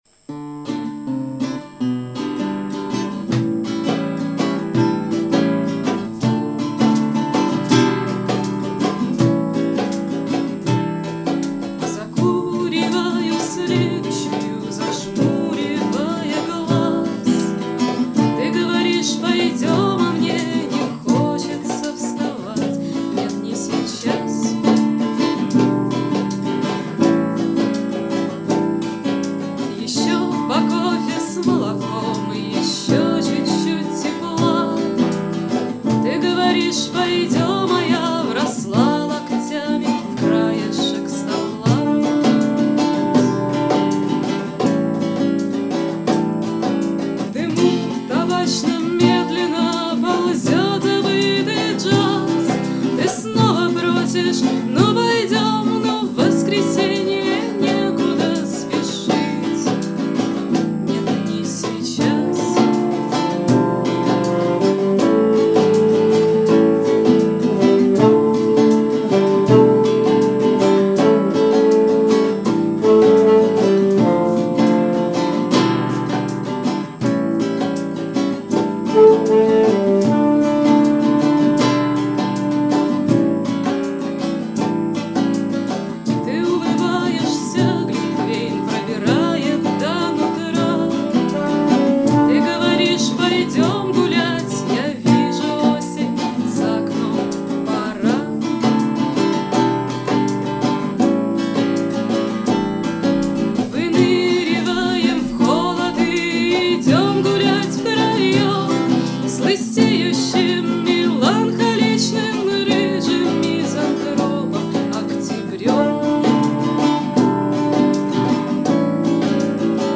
запись с репетиции 9 декабря 2006 г.